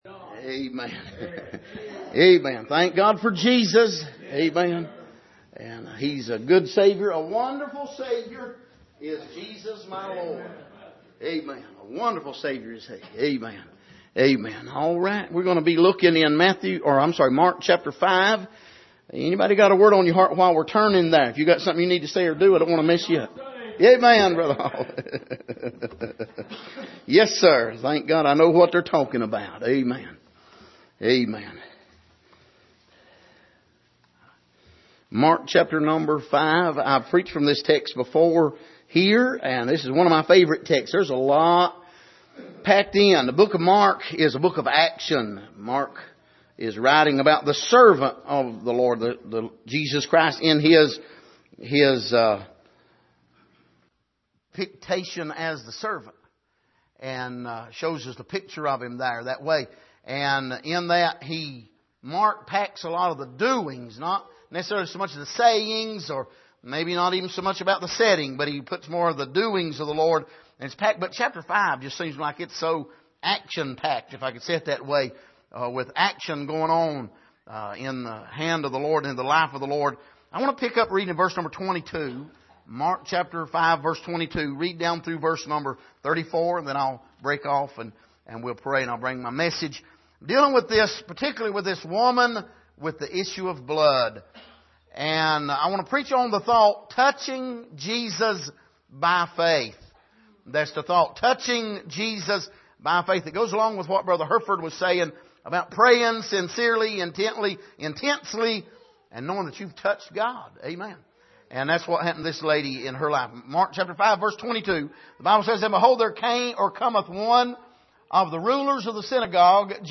Passage: Mark 5:22-34 Service: Sunday Morning Touching Jesus By Faith « The Face of Jesus Christ God Spoke